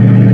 engine5.wav